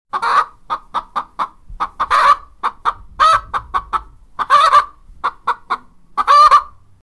Slepička hledá zrníčka po dvorku a u toho si kdáká: „Kokodák!“
slepice.mp3